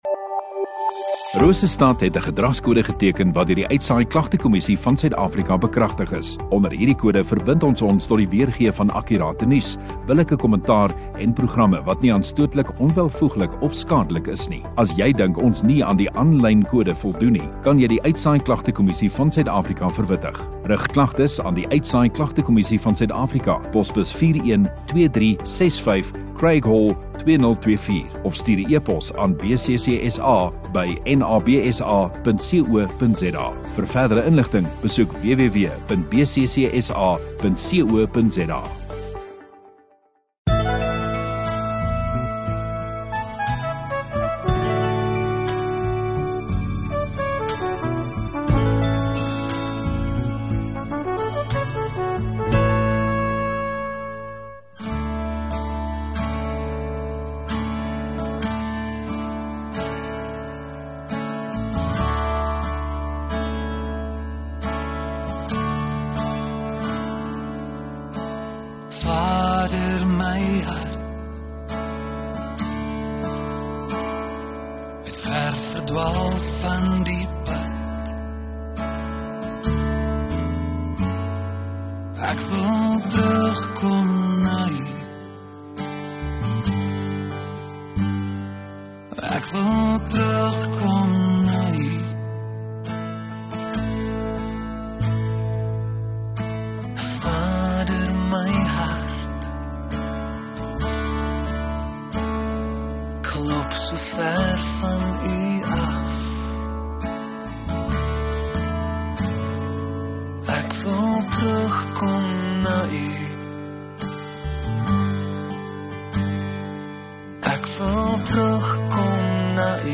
1 Oct Sondagaand Erediens